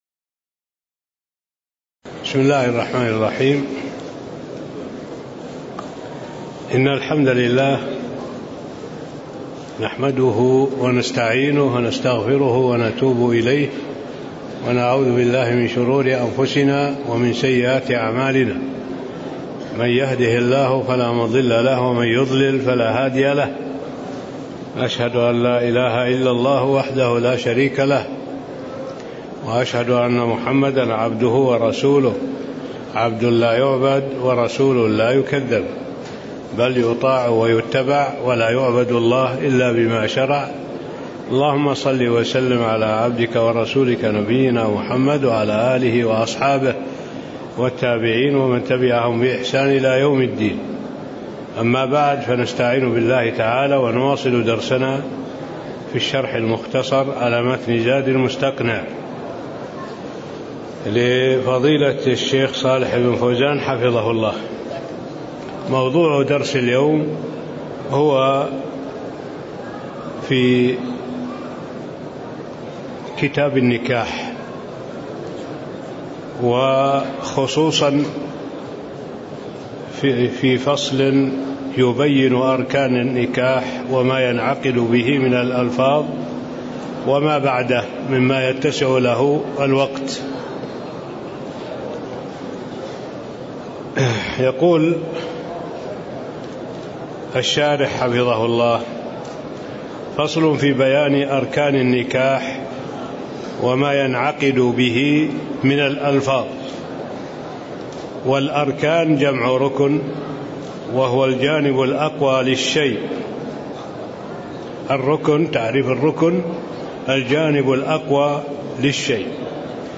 تاريخ النشر ٤ رجب ١٤٣٥ هـ المكان: المسجد النبوي الشيخ: معالي الشيخ الدكتور صالح بن عبد الله العبود معالي الشيخ الدكتور صالح بن عبد الله العبود فصل بيان اركان النكاح (01)